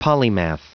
Prononciation du mot polymath en anglais (fichier audio)
Prononciation du mot : polymath